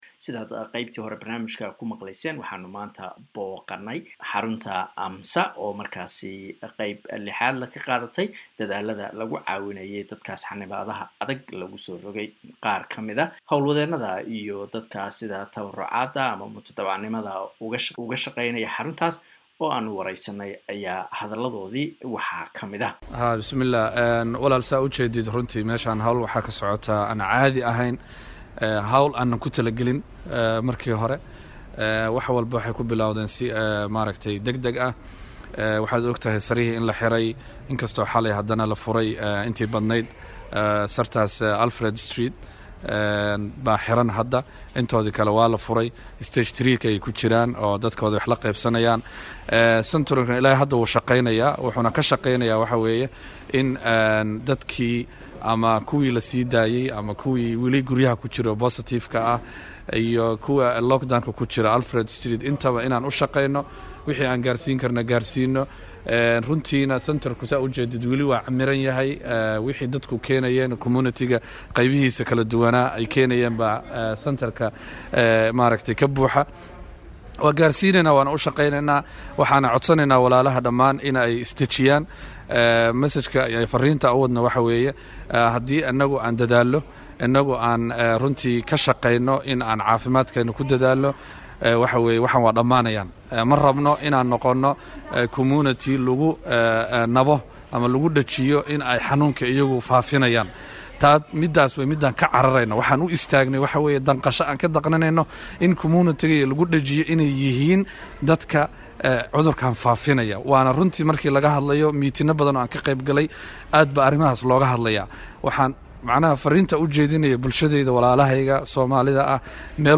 Waraysi aan la yeelanay howlwadeenada iyo shaqaalaha tabarucayaasha ah ee AMSSA.